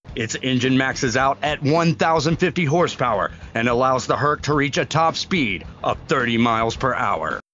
Sports_Announcer_Male.wav